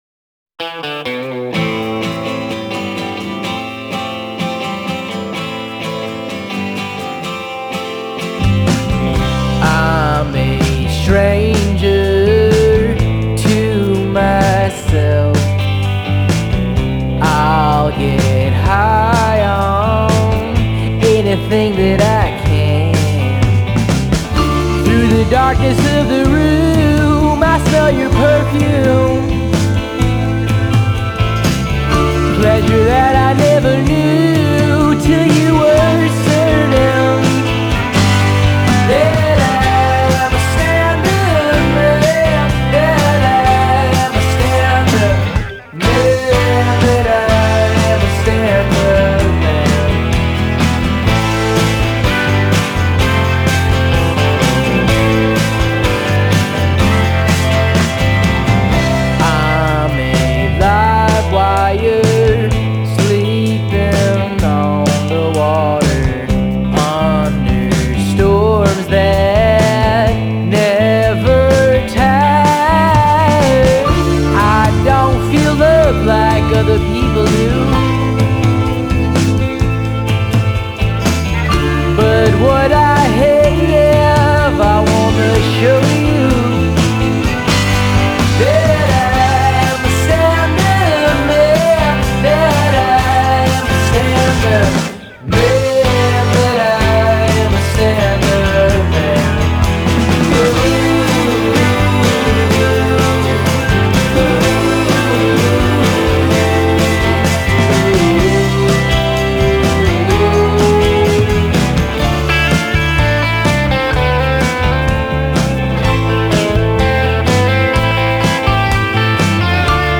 Genre: Alt Folk, Americana